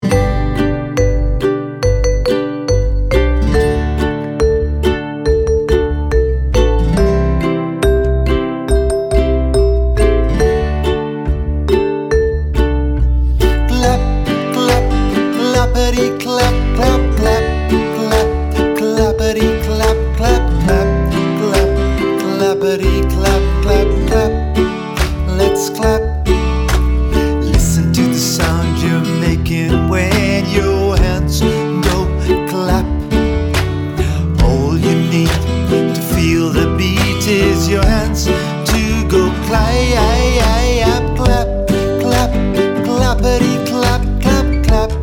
(clapping to the beat)